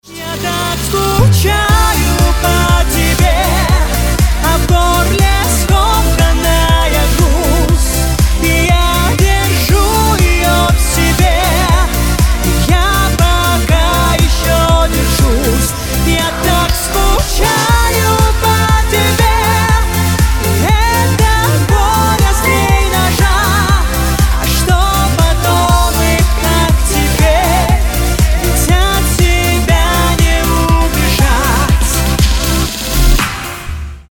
поп
женский вокал
романтические